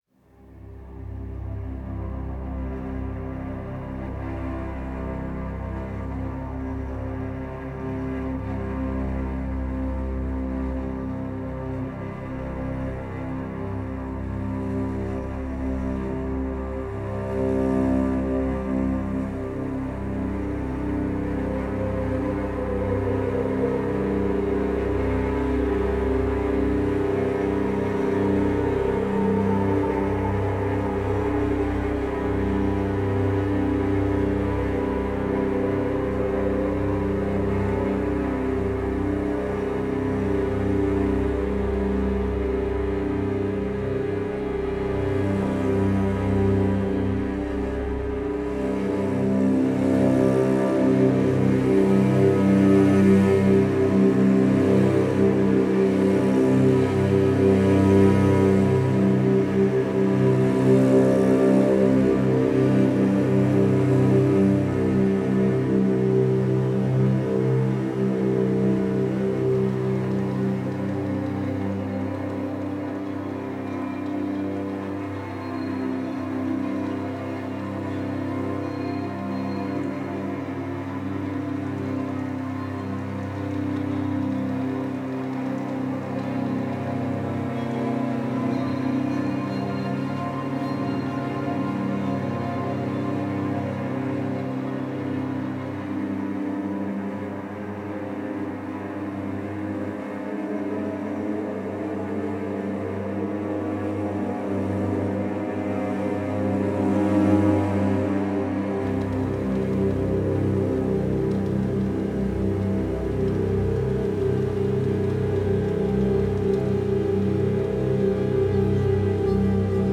Dark, brooding low strings churn beneath the surface.